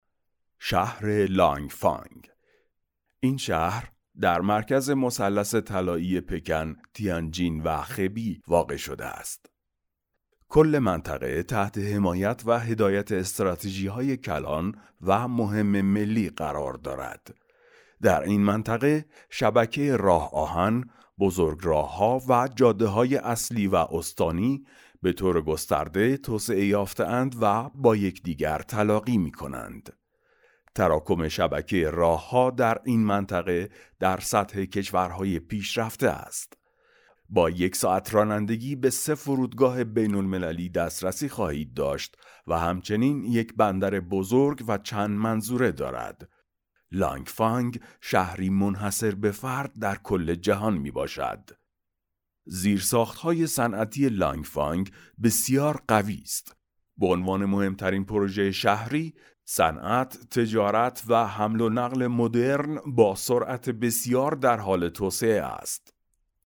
Male
Adult
Naration 2